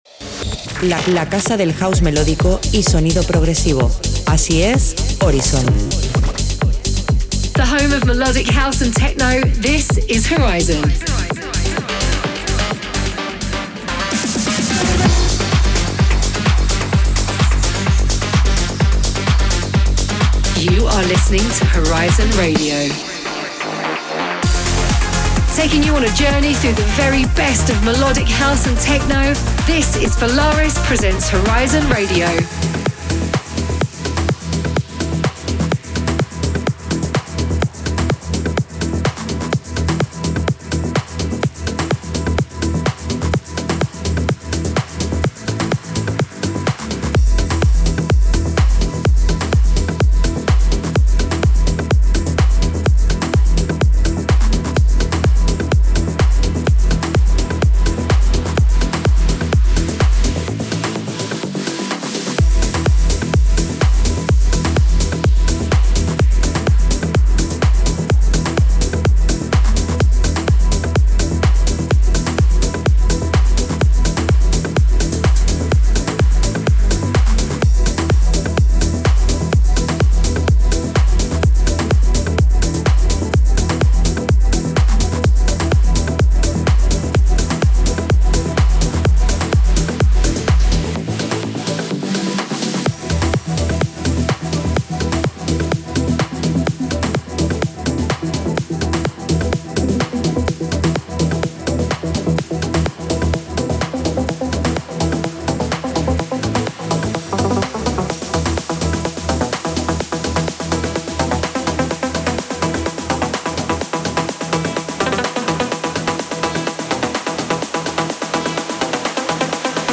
the home of melodic, house and techno.